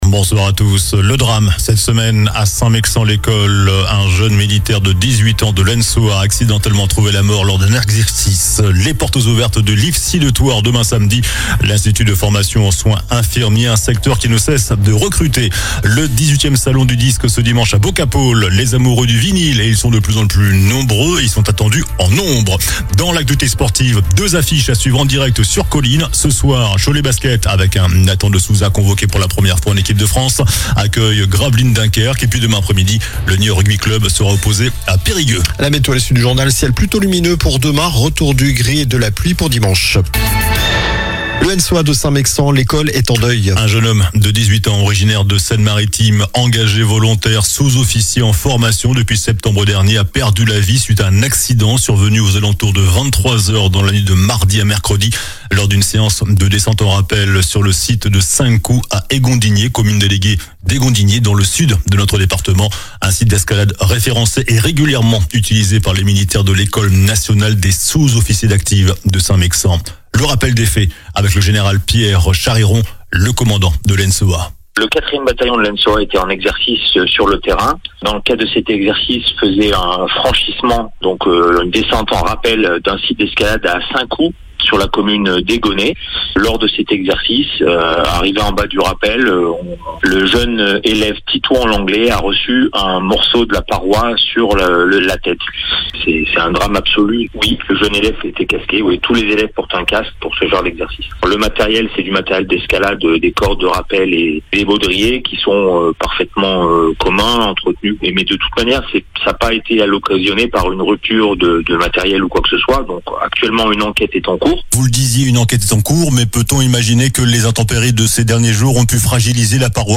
JOURNAL DU VENDREDI 13 FEVRIER ( SOIR )